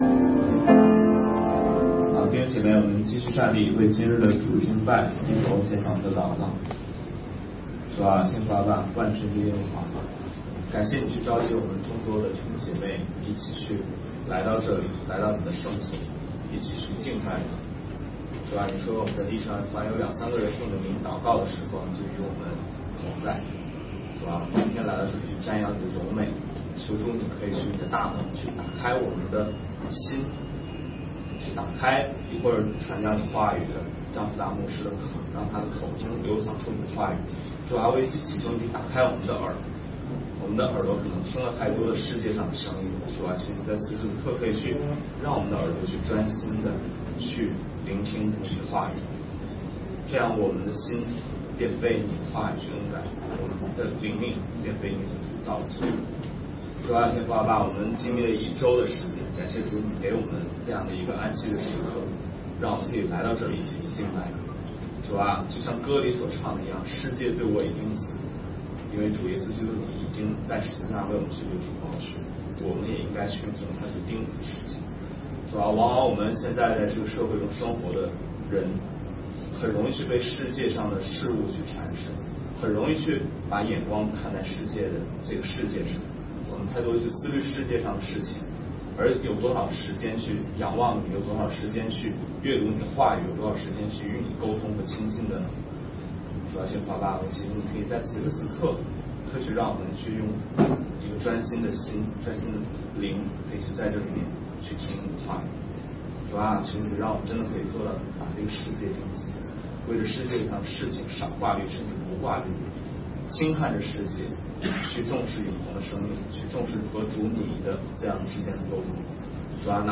加拉太书第3讲 保罗使徒的职分 2018年8月12日 下午4:14 作者：admin 分类： 加拉太书圣经讲道 阅读(4.91K